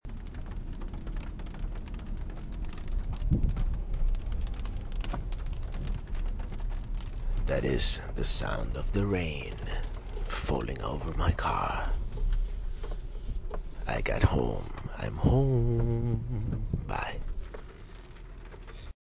Rain
35683-rain.mp3